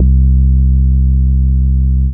Klick Bass 65-11.wav